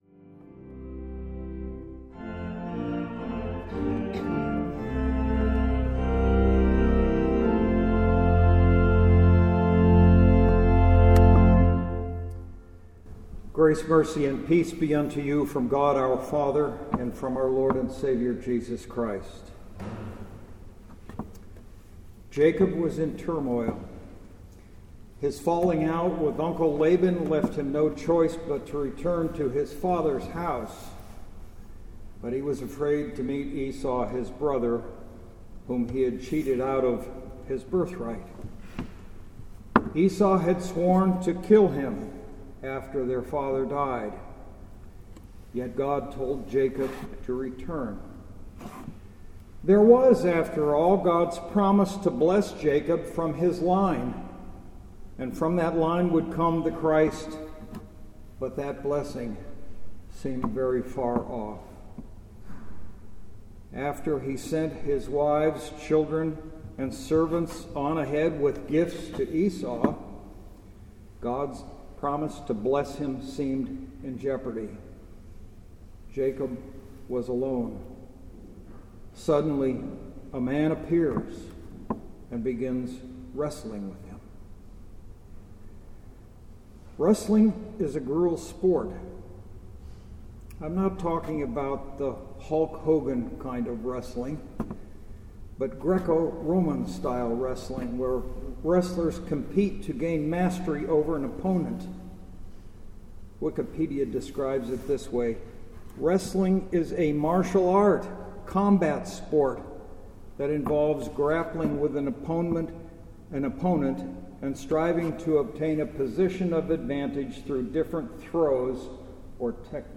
Reminiscere – The Second Sunday in Lent